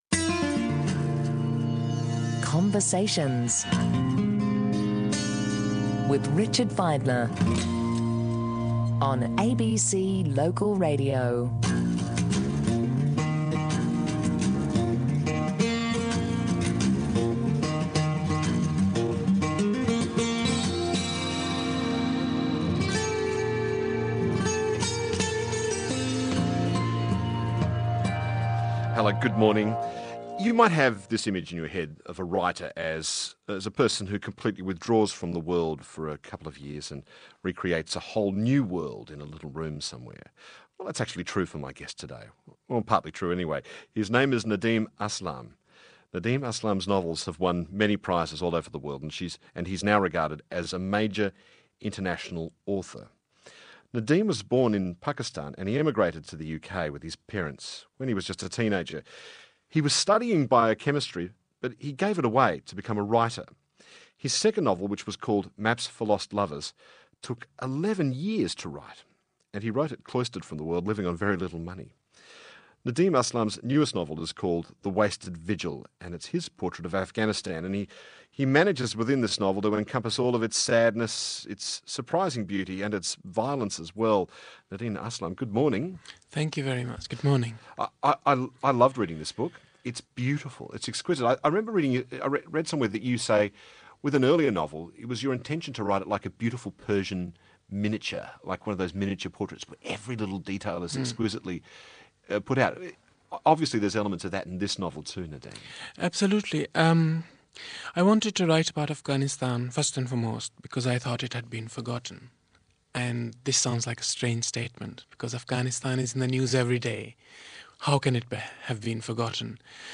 You can listen to this week's ABC 612 interview with Aslam on the Conversation Hour.